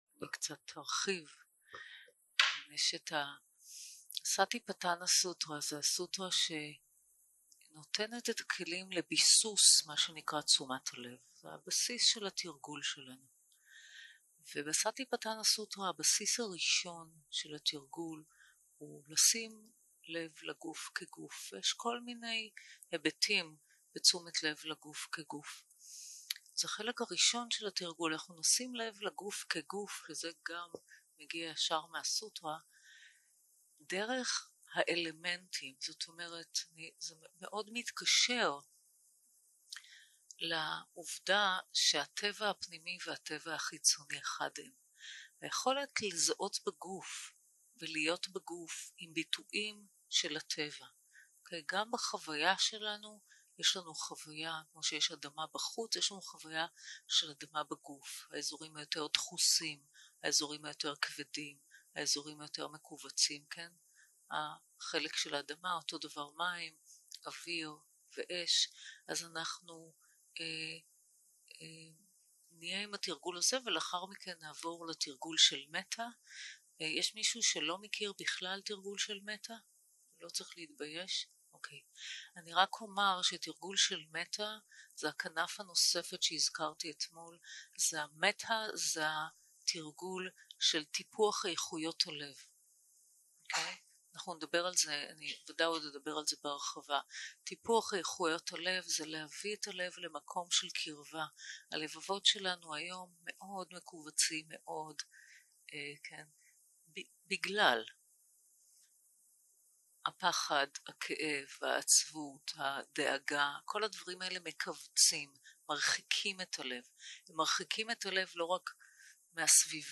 יום 2 - הקלטה 3 - צהרים - מדיטציה מונחית - סטיפטנא סוטרא ומטא 0:00 0:00 סוג ההקלטה: Dharma type: Guided meditation שפת ההקלטה: Dharma talk language: Hebrew